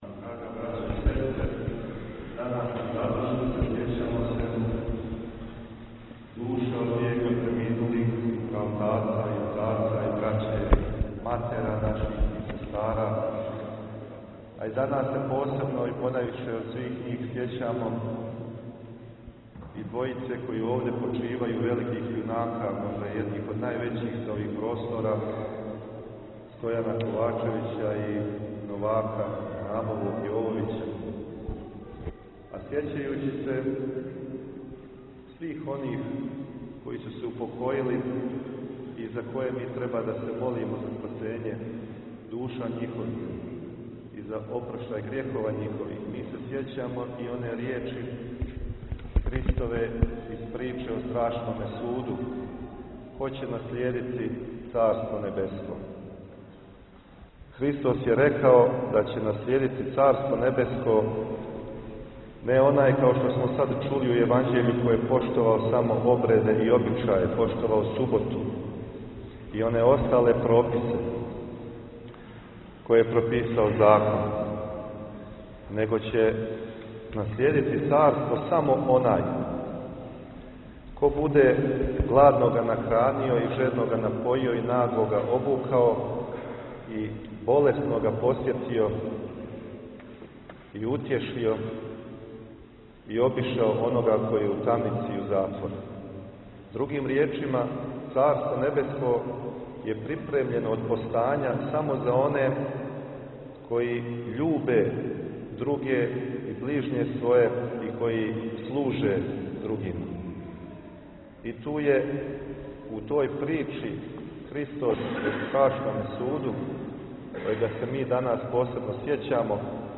Рукоположење у Саборном храму у Никшићу